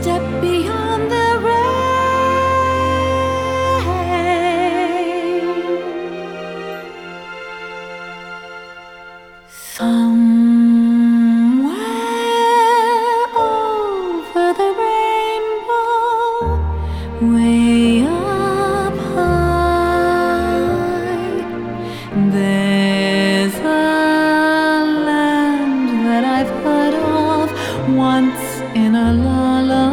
Genre: Vocal